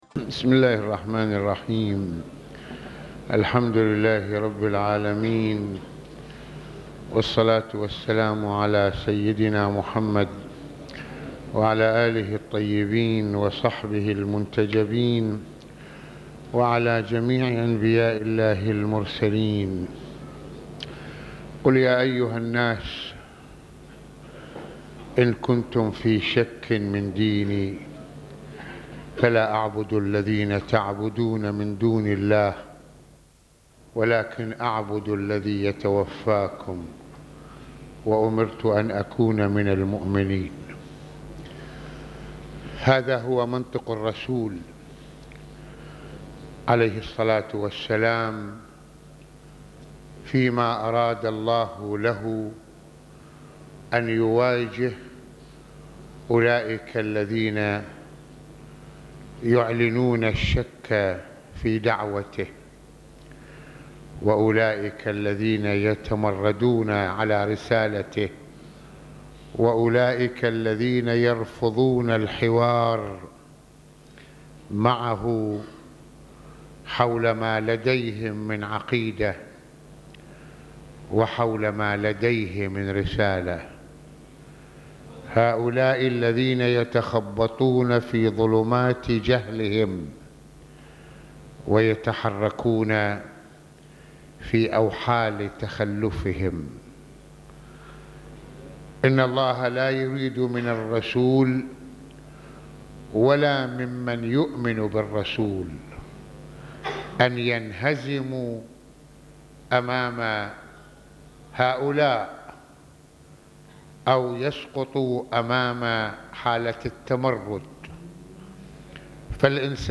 - يتحدّث العلامة المرجع السيّد محمّد حسين فضل الله(رض) في هذه المحاضرة عن أولئك المتخبطون في ظلمات جهلهم في مقابل المؤمنون المنطلقون من إيمانهم العميق وانفتاحهم على الحق الذي يزودهم بكل قوّة وثقة بفضل ثقتهم بربهم وتأييده لهم..